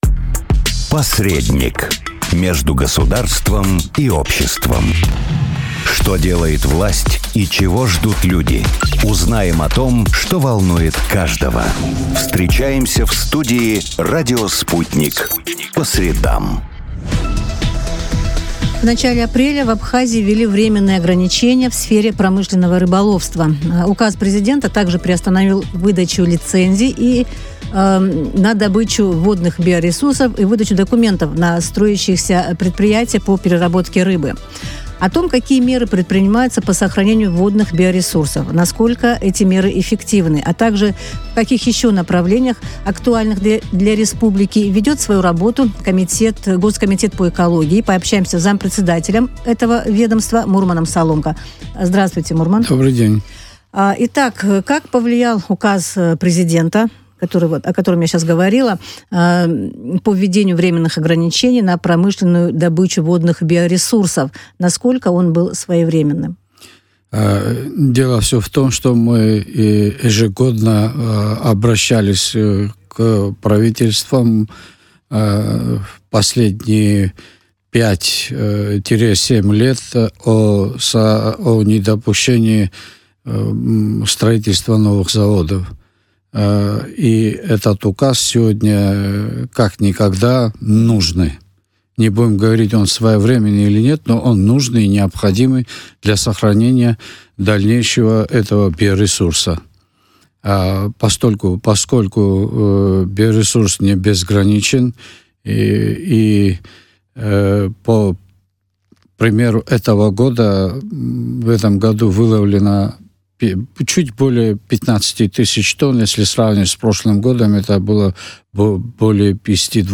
Зампредседателя Госкомитета Абхазии по экологии и природопользованию Мурман Соломко в эфире радио Sputnik рассказал, какие меры принимаются для сохранения водных биоресурсов страны.